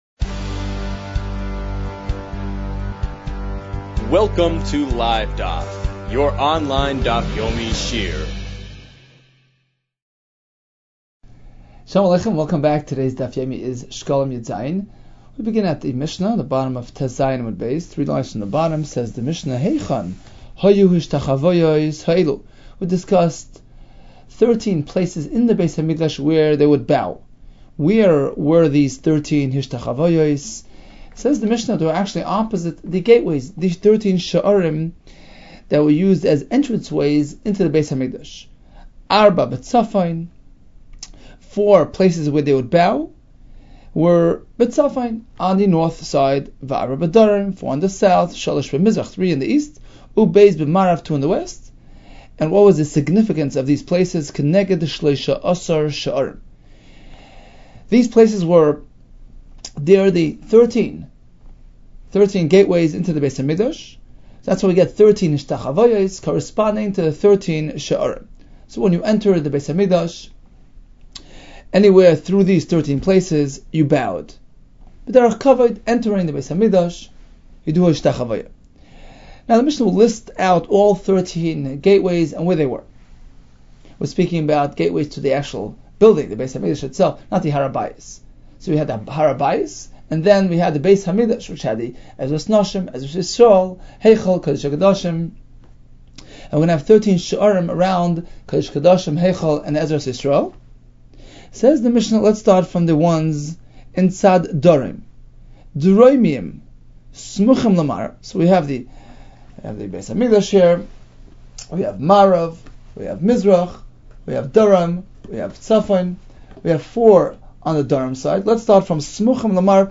In depth Daf Yomi – דף היומי בעיון